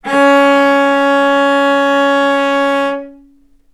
vc-C#4-ff.AIF